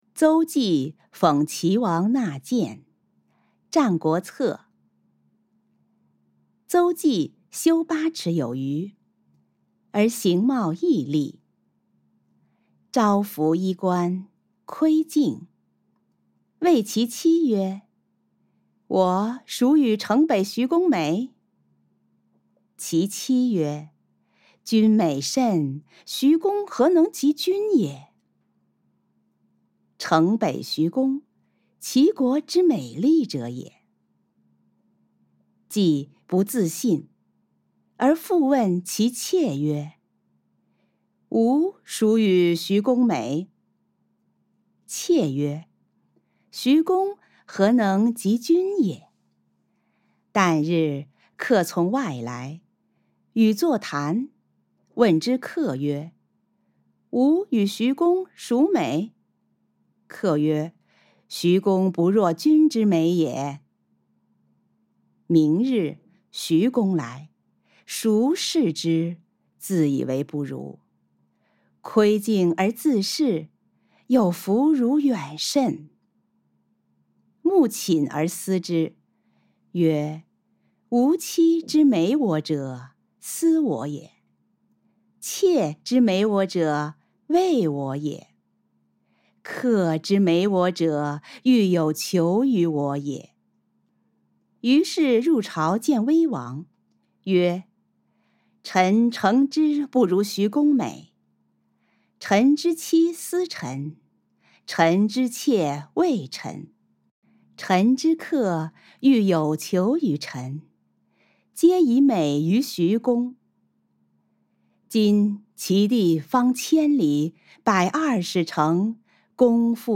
誦讀錄音